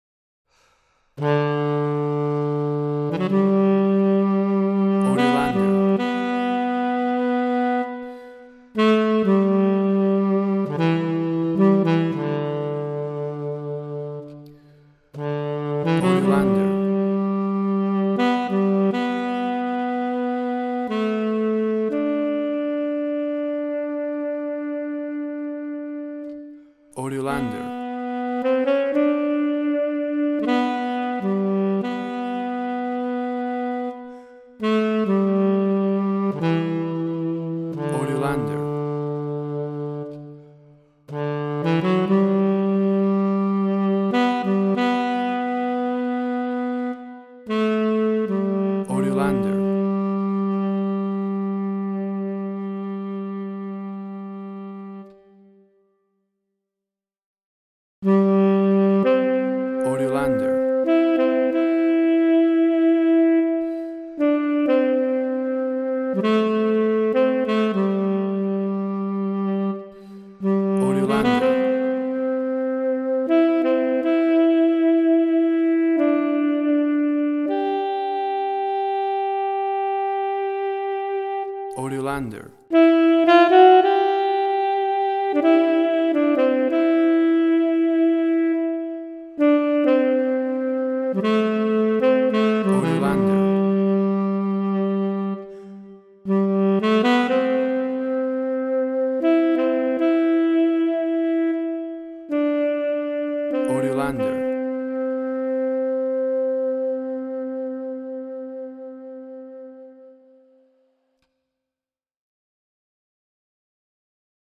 alto saxophone
classic Hymn
Tempo (BPM): 166